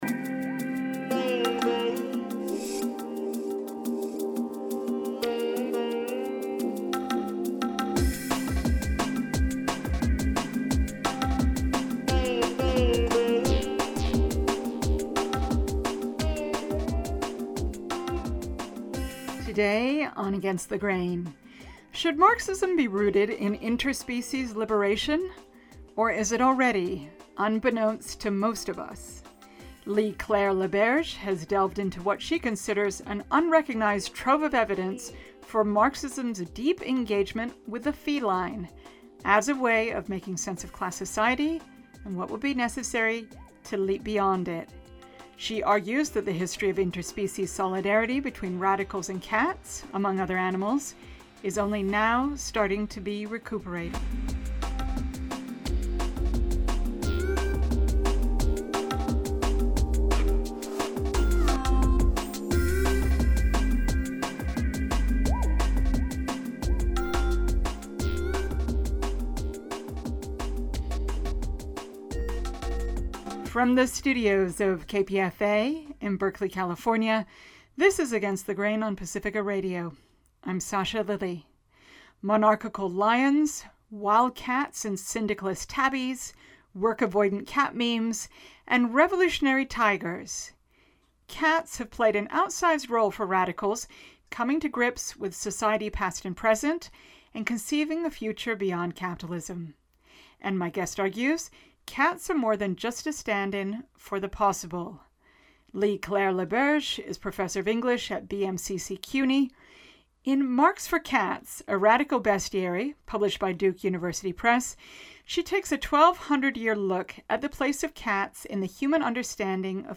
is presented in an original sound collage with archival news reports and the friends’ favorite music